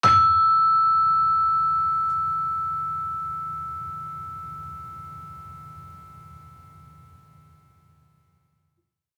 Gamelan Sound Bank
Gender-3-E5-f.wav